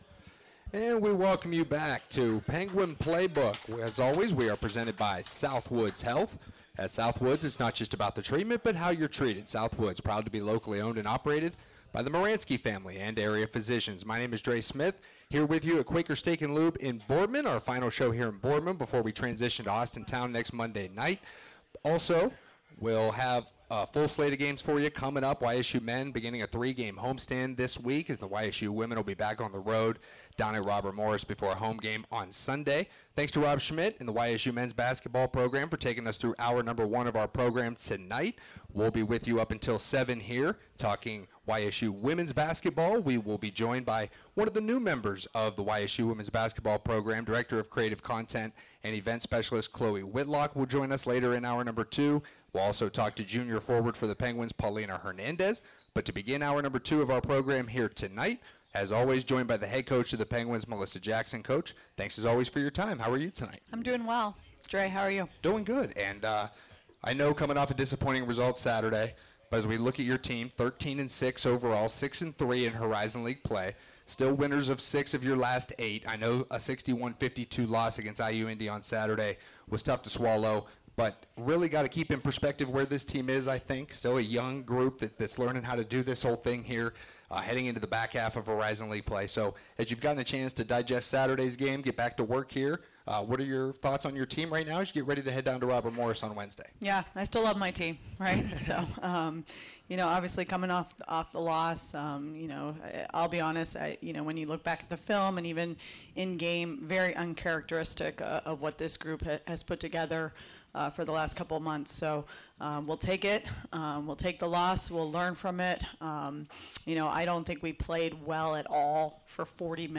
Penguin Playbook Radio Show